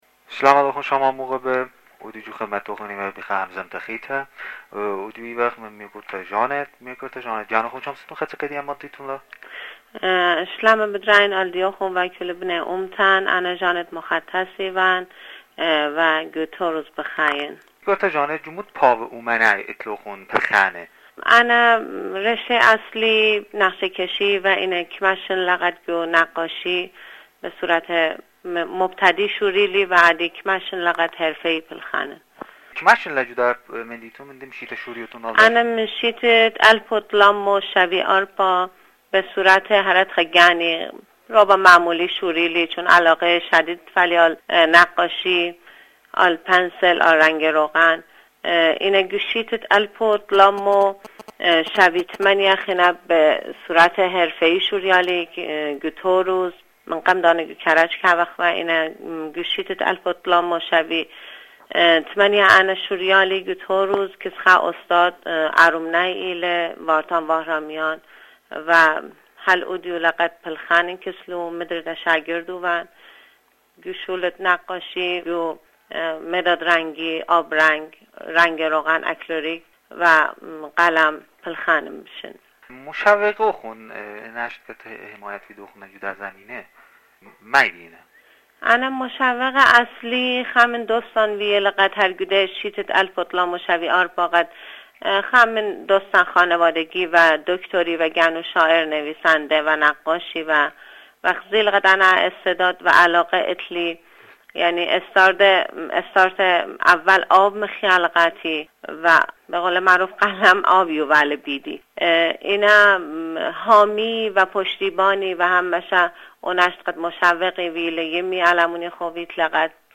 مصاحیه رادیویی